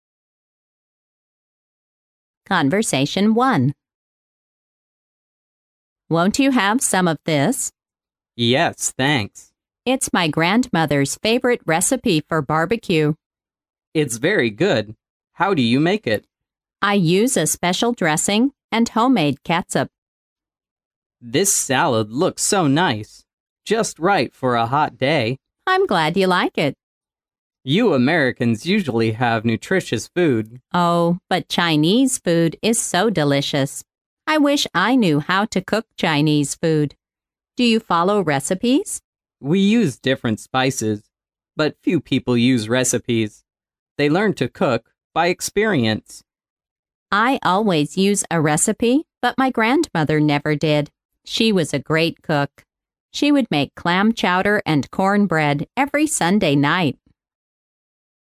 潮流英语情景对话张口就来Unit7：祖母的最爱mp3